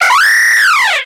Cri de Brutalibré dans Pokémon X et Y.